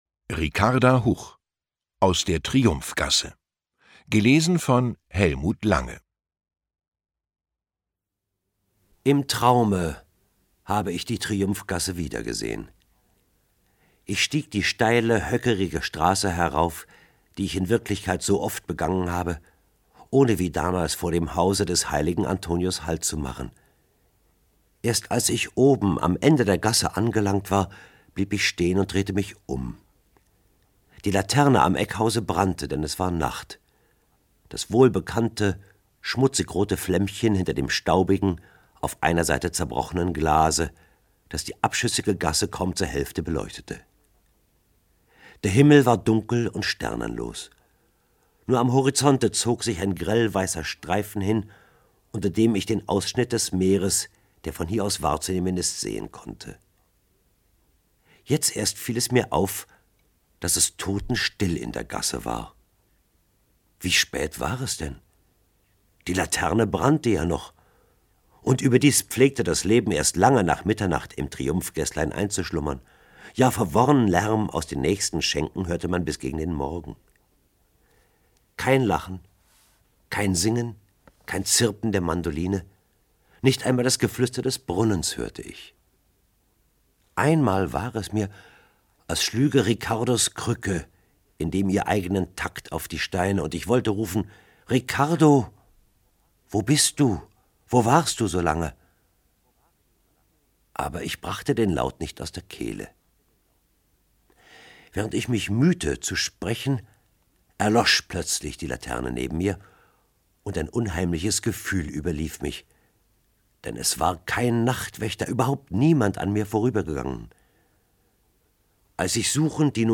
Lesung mit Hellmut Lange (1 mp3-CD)
Hellmut Lange (Sprecher)